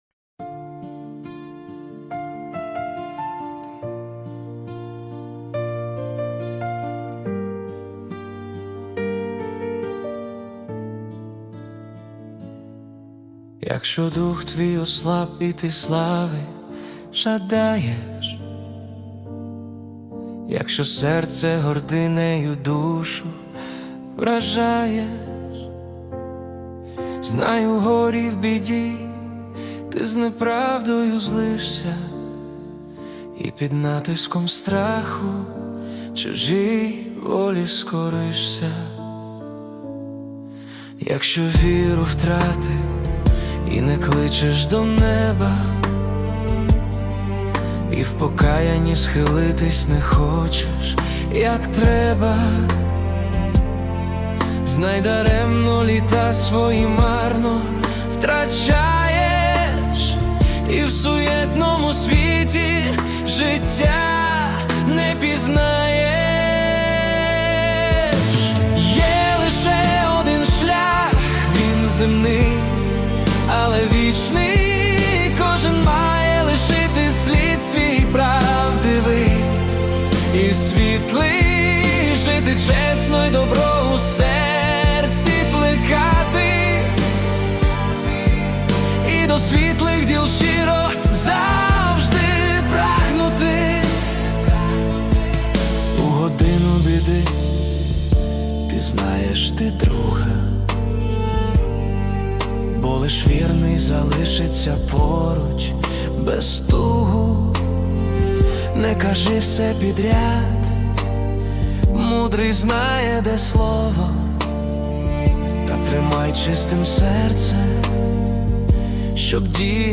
85 просмотров 123 прослушивания 15 скачиваний BPM: 80